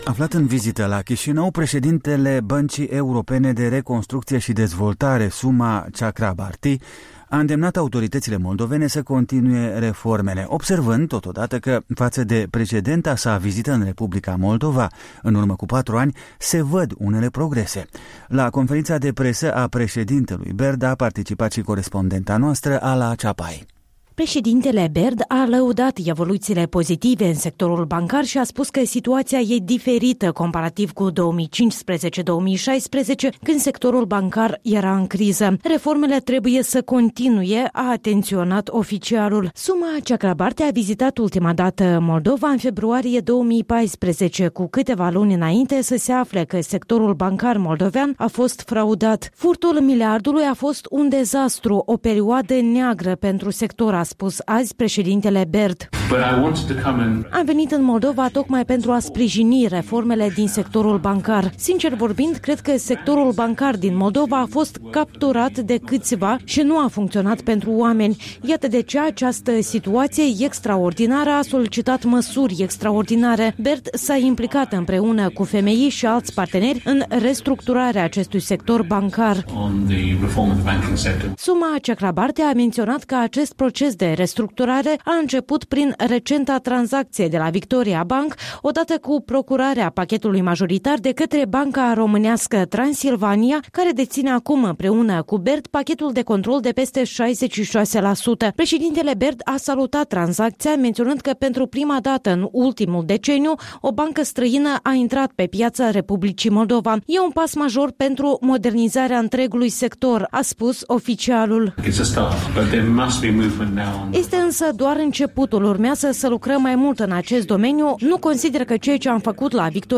Declaraţiile lui Suma Chakrabarti pentru jurnaliştii de la Chişinău.